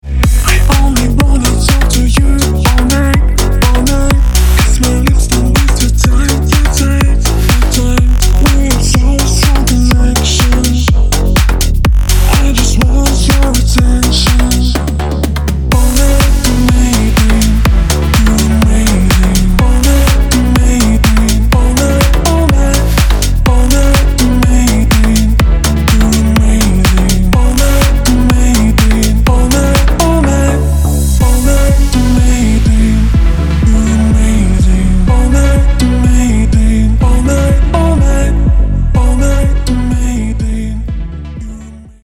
• Качество: 320, Stereo
громкие
мощные
slap house